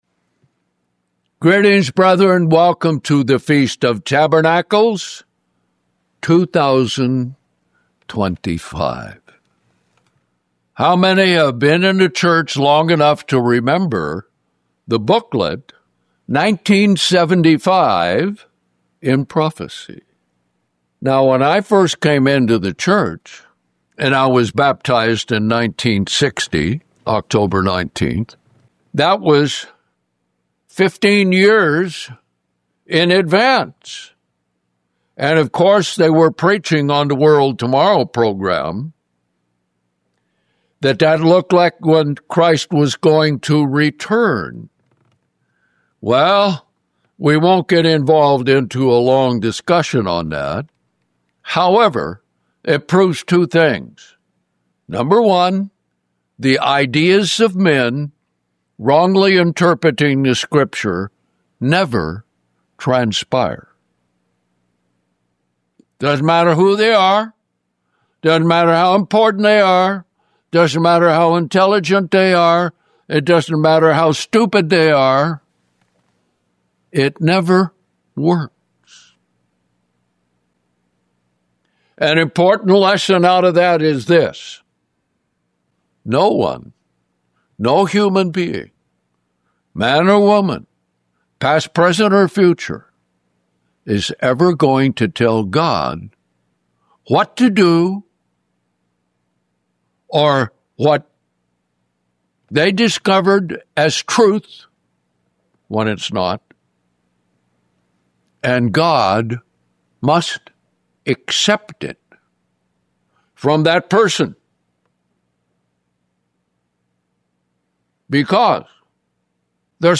(FOT Day 1)